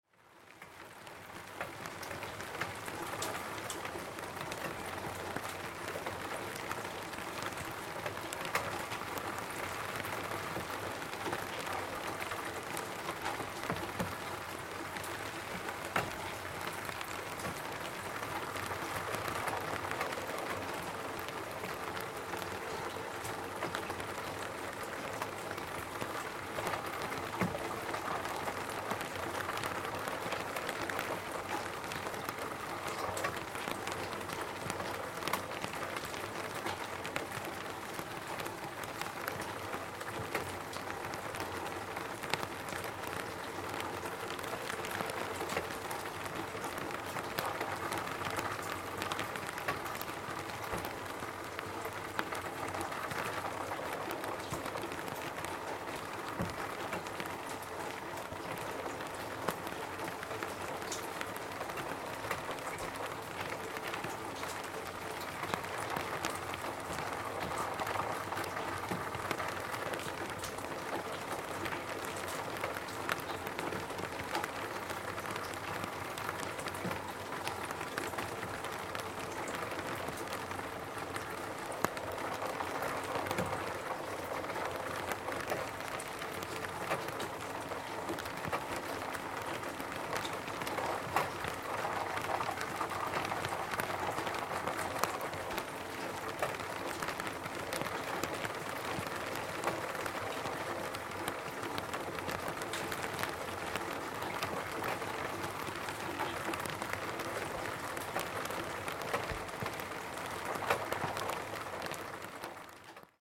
Raindrops On Plastic Roof ringtone free download
Sound Effects